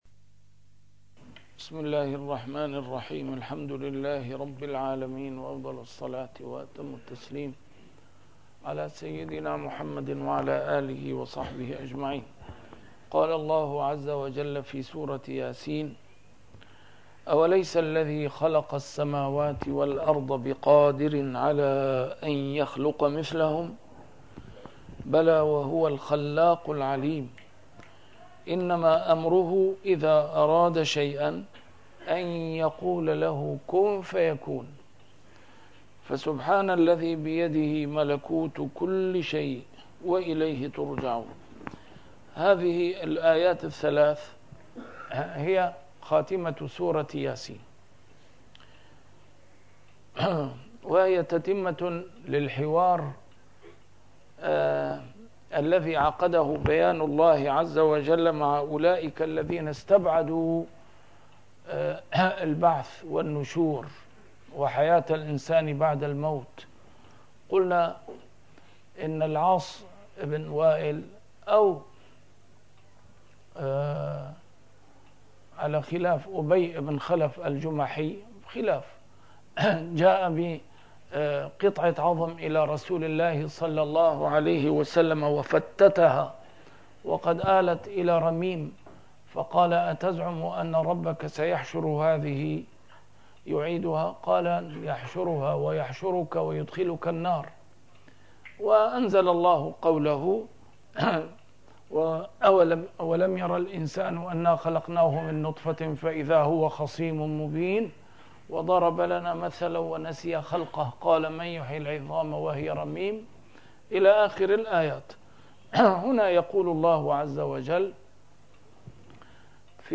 A MARTYR SCHOLAR: IMAM MUHAMMAD SAEED RAMADAN AL-BOUTI - الدروس العلمية - تفسير القرآن الكريم - تسجيل قديم - الدرس 443: يس 81-83
تفسير القرآن الكريم - تسجيل قديم - A MARTYR SCHOLAR: IMAM MUHAMMAD SAEED RAMADAN AL-BOUTI - الدروس العلمية - علوم القرآن الكريم - الدرس 443: يس 81-83